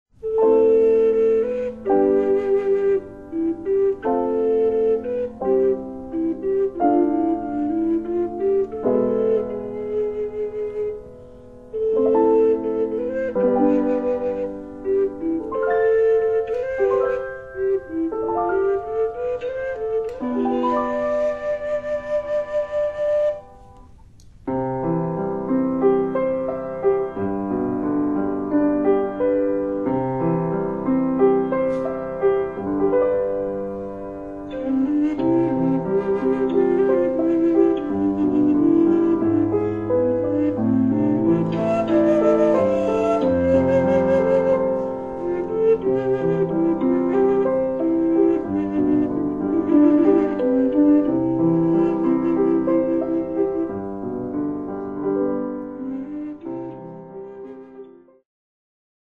音楽ファイルは WMA 32 Kbps モノラルです。
Recorder、Piano
（244,470 bytes） あせらずゆっくり進んで行こうというテーマの、ほっとするような 1 曲。